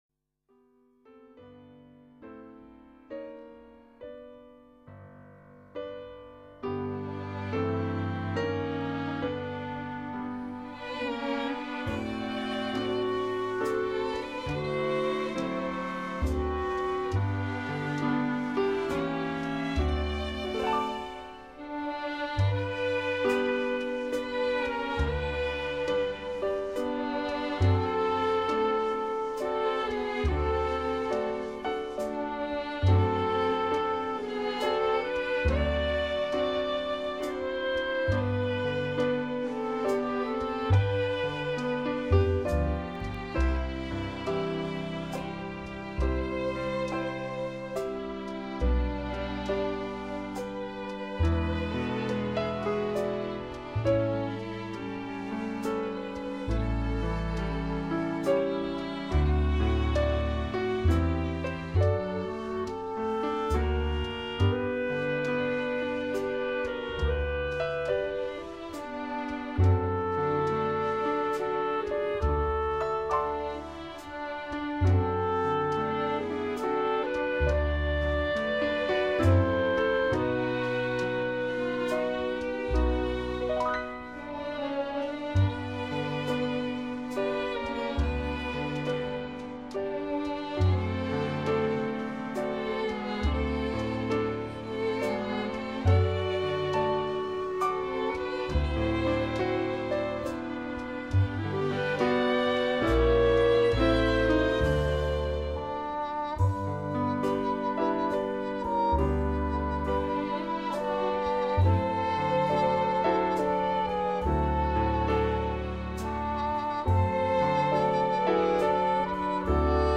它以一种含蓄怀旧的情调
很舒服的音乐，我最喜欢那幅洗头的剧照，温馨……。